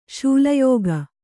♪ śula yōga